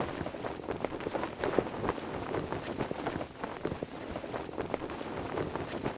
target_wind_float_clothloop.ogg